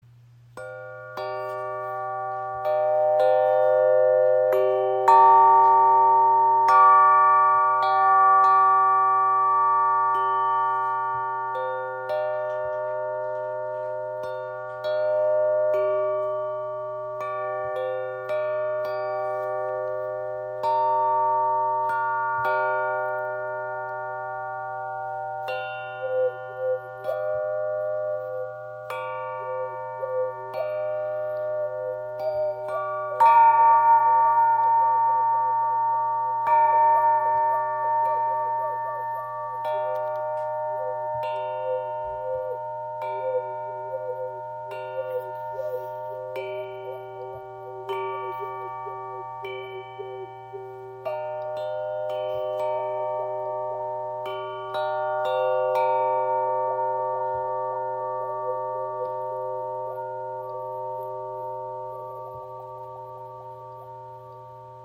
Wayunki in G & C Minor – Sanfte Klänge in 432 Hz
Gestimmt auf G – C – D♯ – G – C – G in 432 Hz, öffnet sie Herz- und Kehlchakra und erzeugt ein tiefes, ausdrucksvolles Klangbild mit sanften Obertönen.